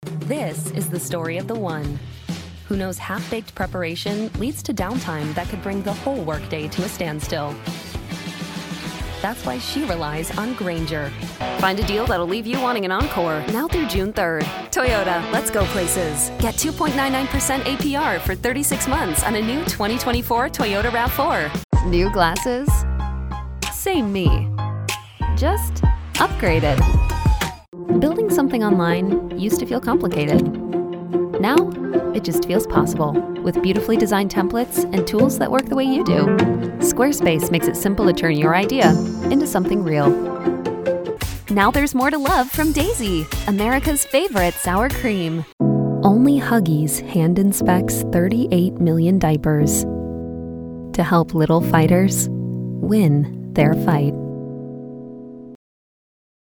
Voiceover : Commercial : Women